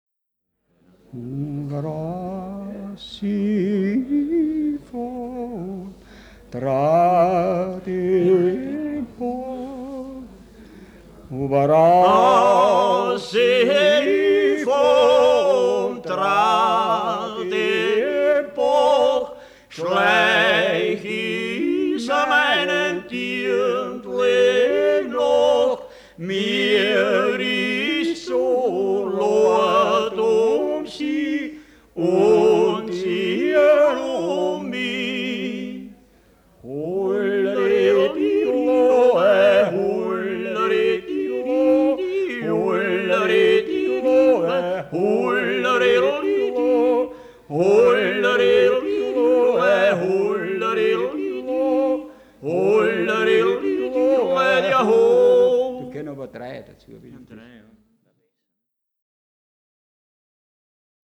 CD 1_Titel 2: WeXel oder Die Musik einer Landschaft Teil 2.1 - Das Weltliche Lied - Ungeradtaktig: Jodler und Jodler-Lied – Ungeradtaktig (E-BOOK - o:1613)